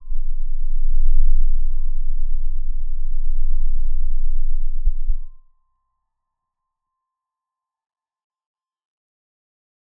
G_Crystal-C0-f.wav